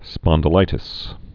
(spŏndl-ītĭs)